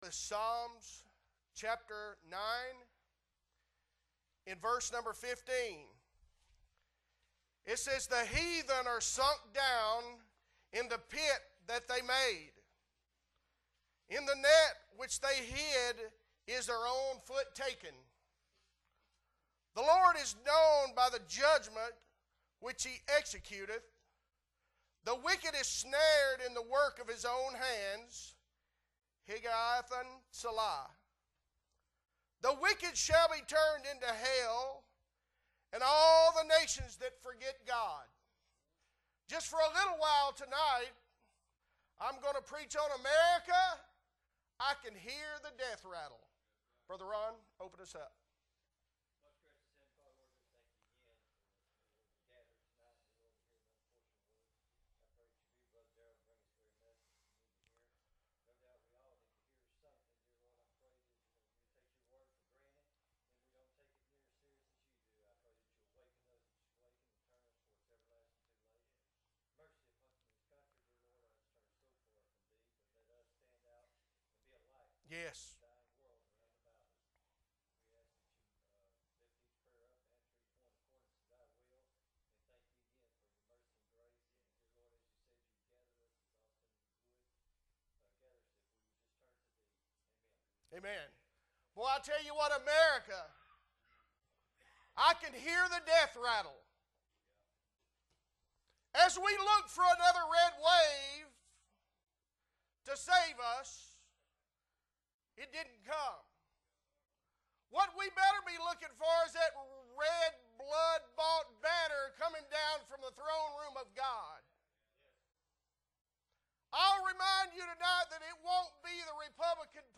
November 13, 2022 Sunday Evening Service - Appleby Baptist Church